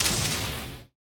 foundry-blade.ogg